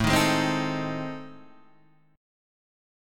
AmM7b5 chord {x x 7 8 9 8} chord